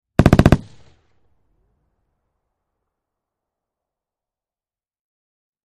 HK-21 Machine Gun Burst From Medium Point of View, X4